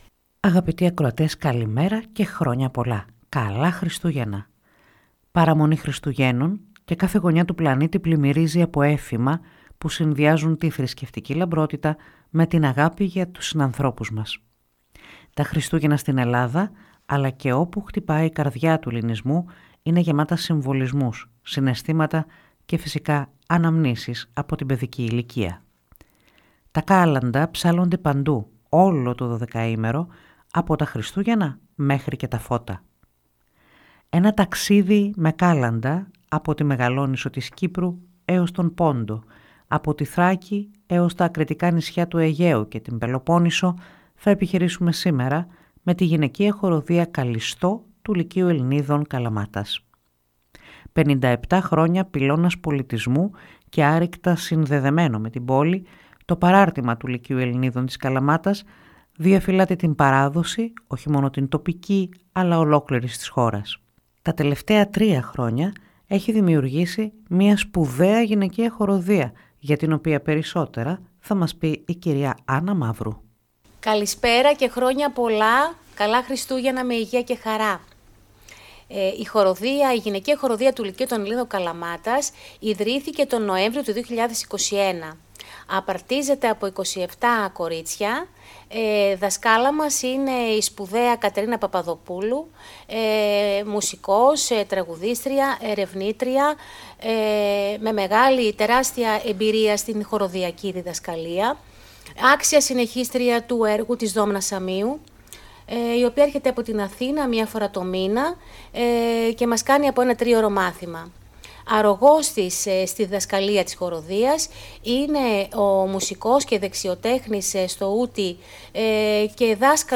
γυναικεία χορωδία
Στα παραδοσιακά κάλαντα Χριστουγέννων και Πρωτοχρονιάς
6μελής ορχήστρα παραδοσιακών οργάνων